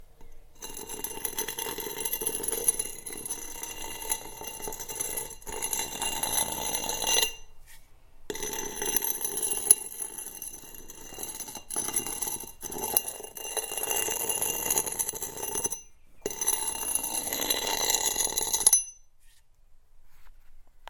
Hatchet stroked against a concrete block
Duration - 20s Environment - This similar to many tracks is recored in a large shed with a corrugated roof. It is slight open on top in which that wall is attached to another shed therefore picking more environmental sounds outside. Description - This is friction set off when impact is made using different materials. Here a hatchet is stroked against a concrete block.